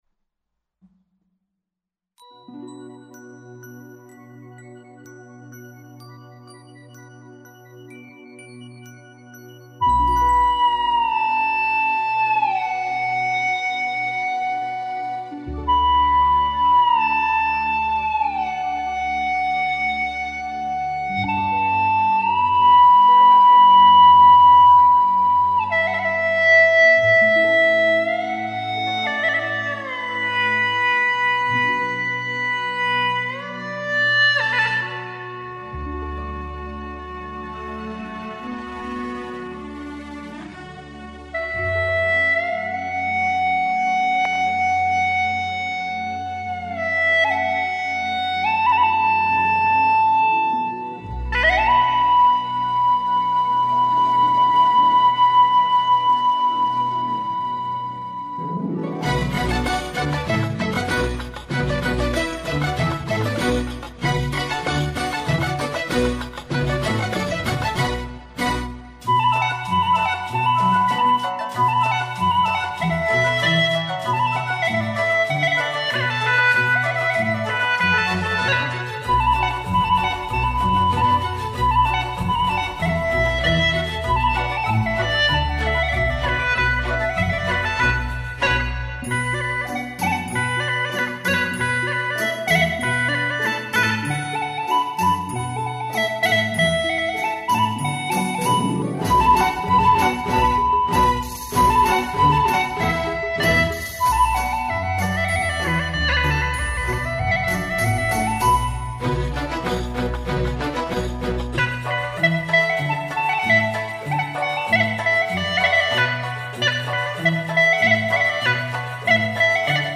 调式 : D 曲类 : 独奏